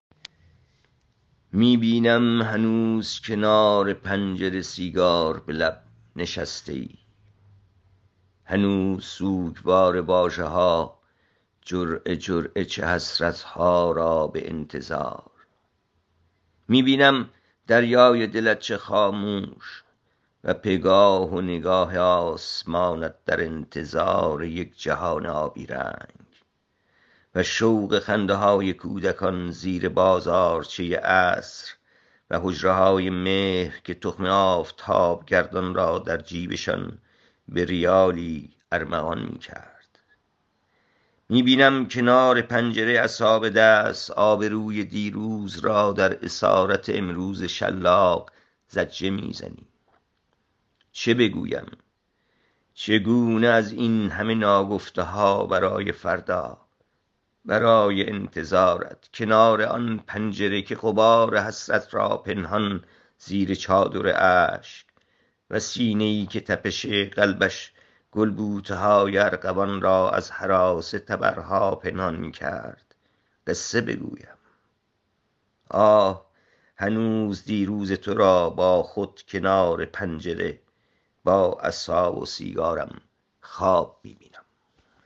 این شعر را با صدای شاعر از این‌جا بشنوید